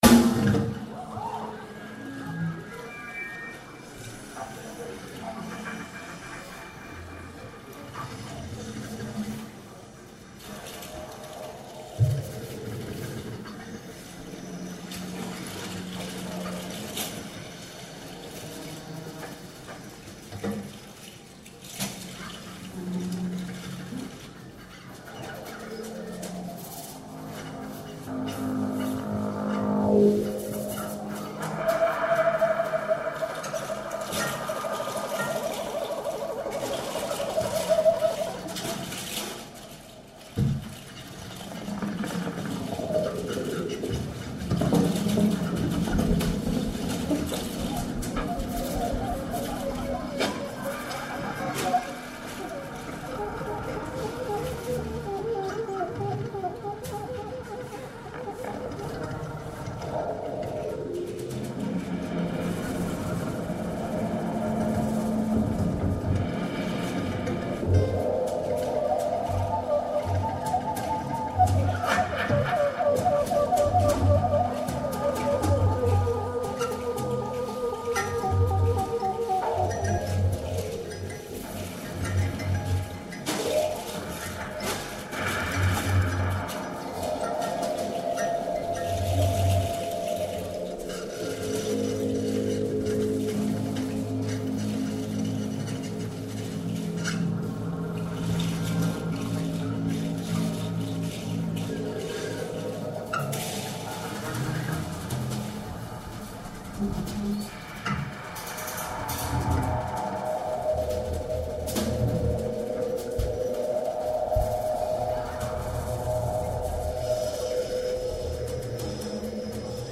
Gitarre
Sopransax
Drums 02.
Bass
Trompete